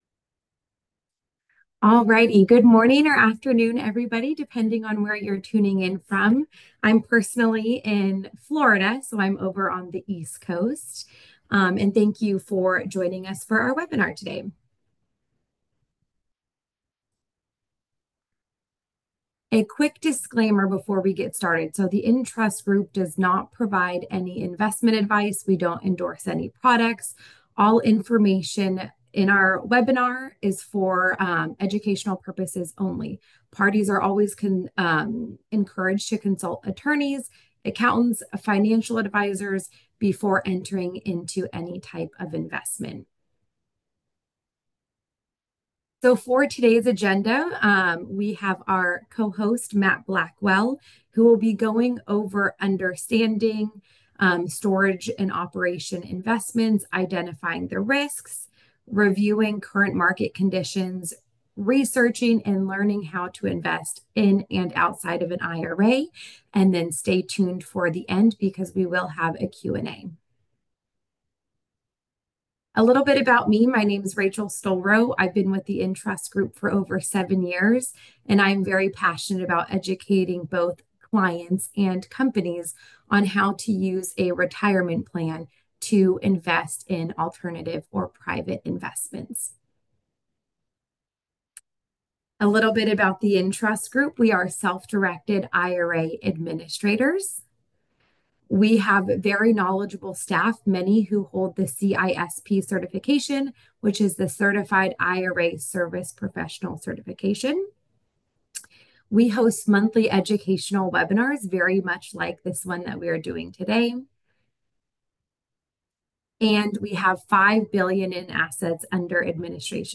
For alternative investors like you, self-storage is another alternative to create passive income for your retirement strategy. Learn how it all works in this webinar.